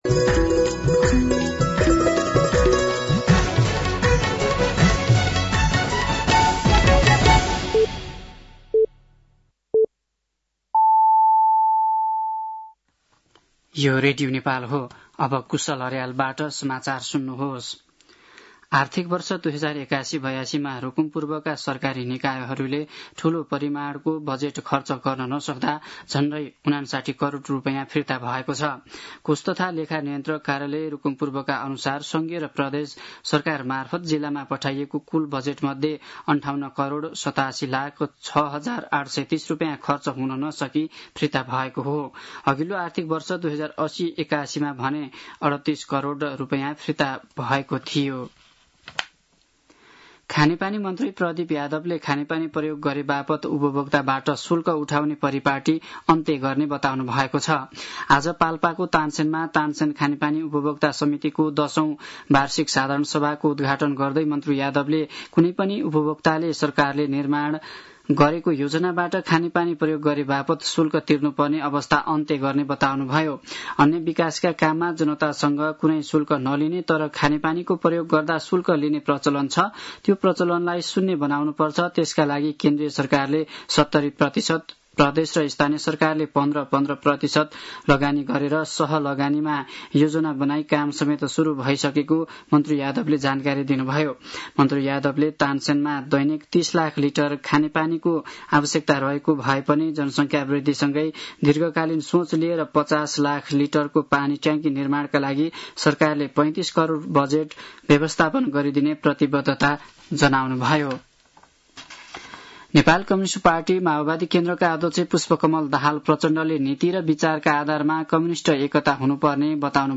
साँझ ५ बजेको नेपाली समाचार : १० साउन , २०८२